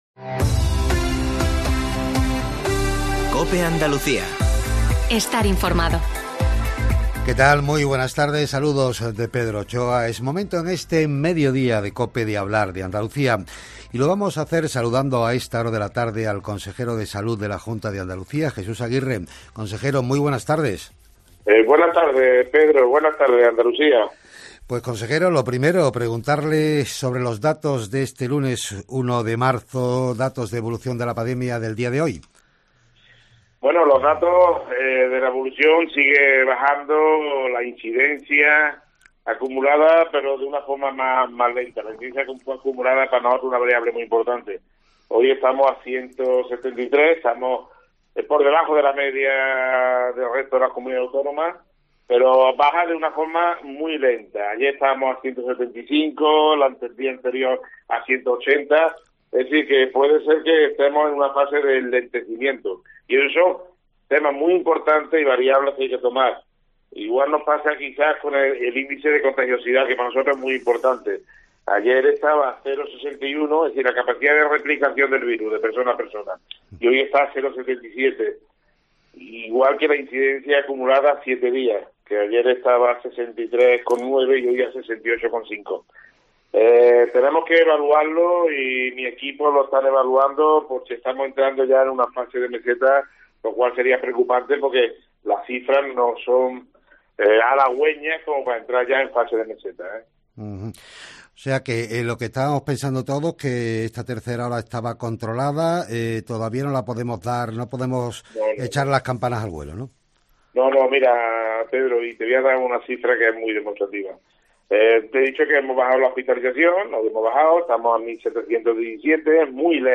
Vuelve a decirnos el consejero de Salud y Familias en una entrevista con COPE Andalucía que puedes escuchar aquí íntegramente.